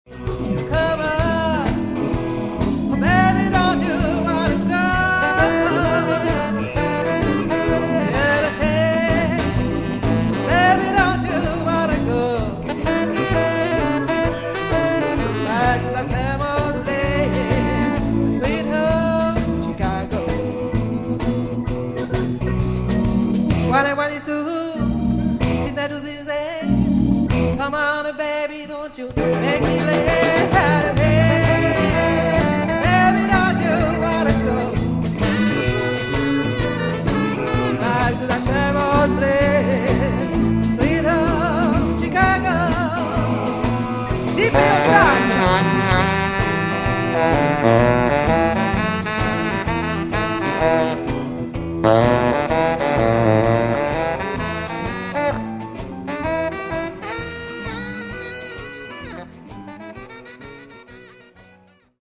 madison